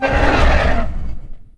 c_horisath_atk1.wav